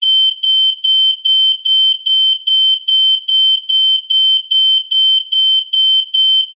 На этой странице собраны звуки датчика дыма — от резкого тревожного сигнала до прерывистого писка.
Домашний датчик дыма предупреждает о возгорании